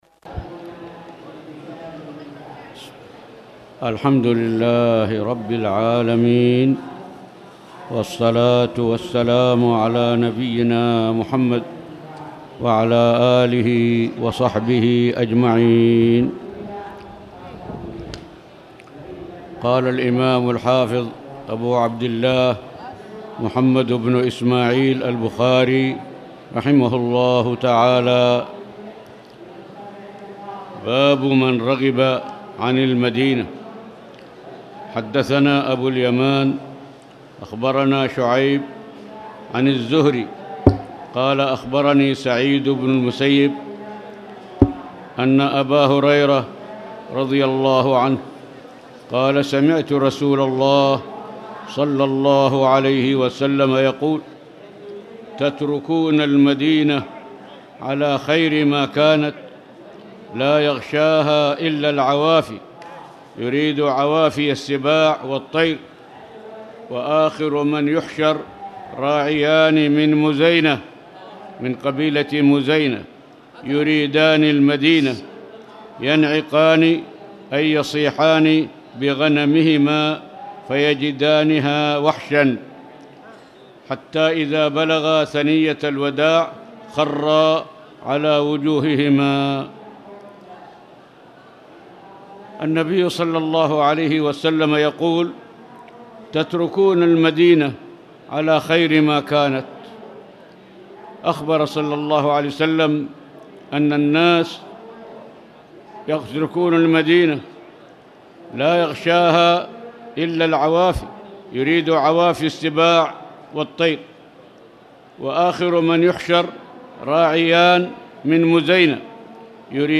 تاريخ النشر ١٥ ربيع الأول ١٤٣٨ هـ المكان: المسجد الحرام الشيخ